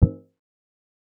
Click (17).wav